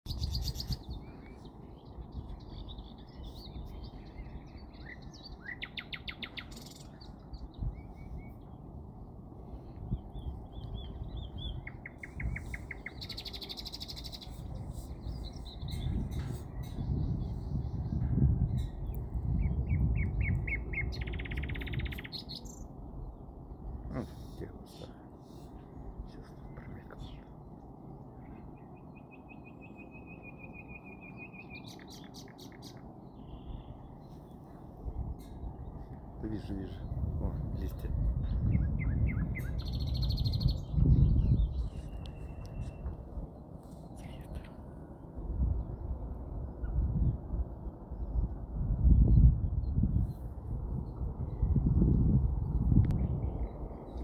Nachtigall Gesang
Melodisch, laut, abwechslungsreich – mit klaren Tönen, Trillern, Pfiffen und Strophen.
Nachtigall-Gesang-Fruehling-Voegel-in-Europa.mp3